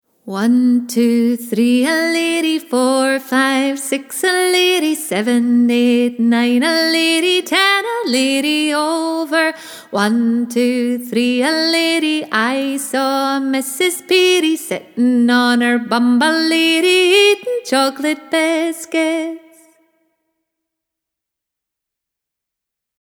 1, 2, 3 Aleerie Player Trad. play stop mute max volume repeat 1, 2, 3 Aleerie Scottish Music Download 1, 2, 3 Aleerie MP3